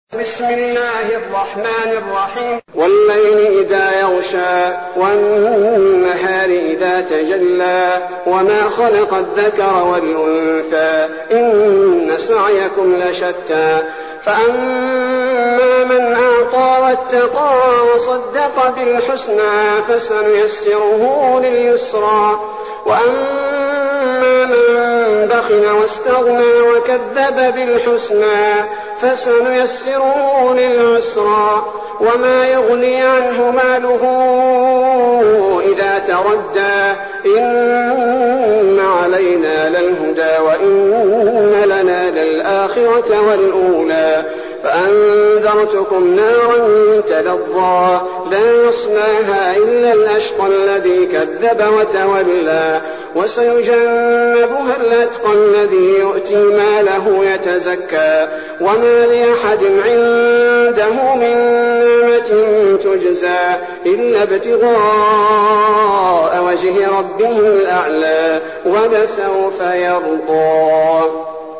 المكان: المسجد النبوي الشيخ: فضيلة الشيخ عبدالباري الثبيتي فضيلة الشيخ عبدالباري الثبيتي الليل